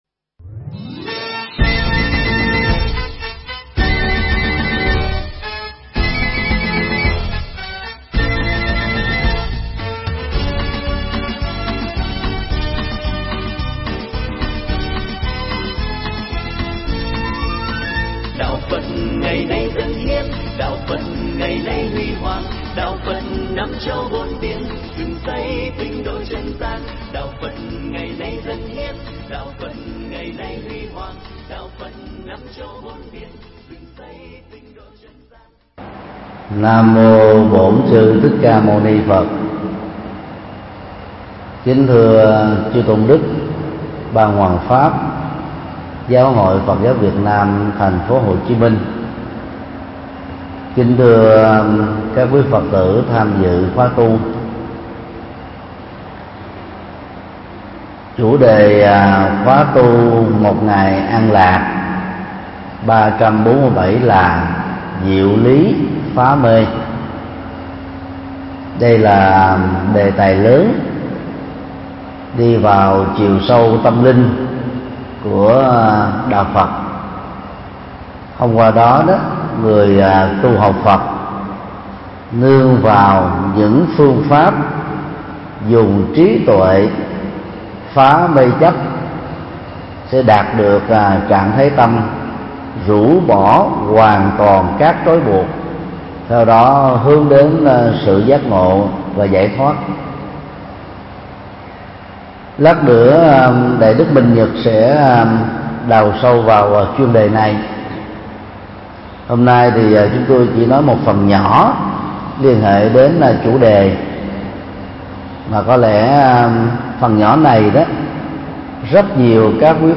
Mp3 Thuyết Giảng Xuất gia gieo duyên
giảng tại chùa Phổ Quang